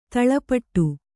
♪ taḷapaṭṭu